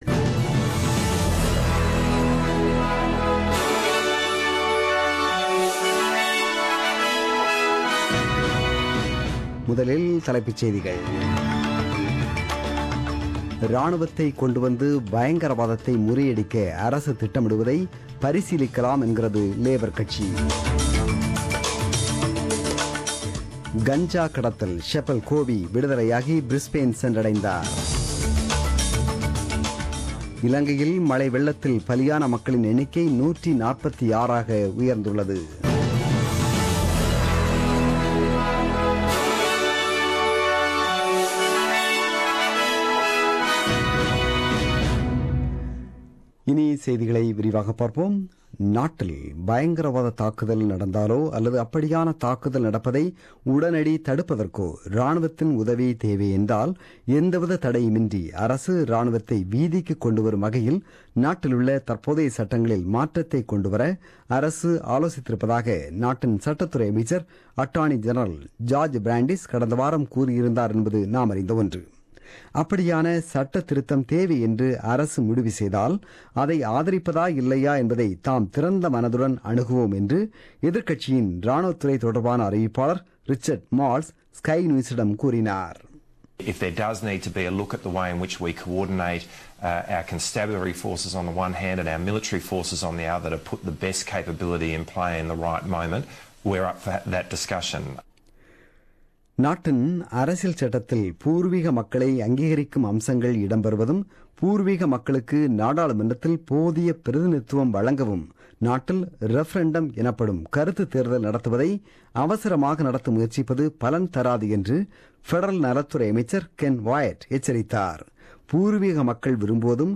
The news bulletin broadcasted on28 May 2017 at 8pm.